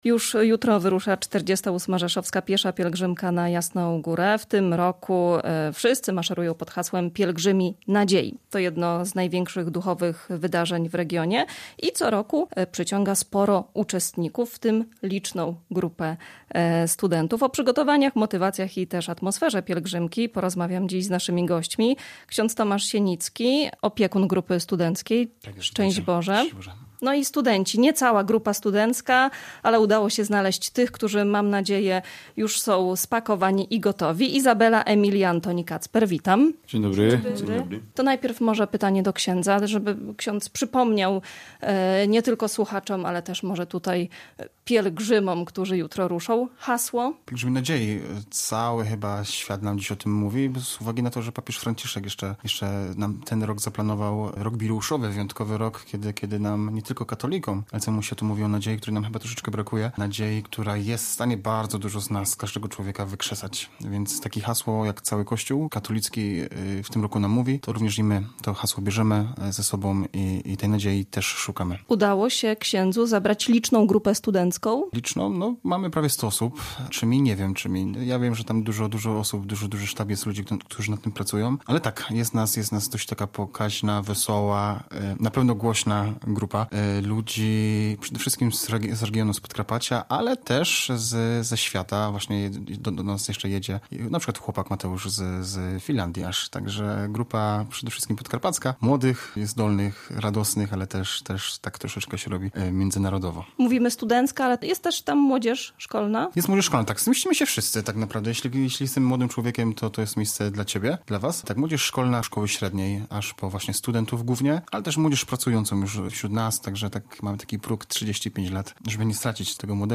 W rozmowie z Radiem Rzeszów podkreślają, że idą po duchowe umocnienie, wyciszenie i nowe relacje.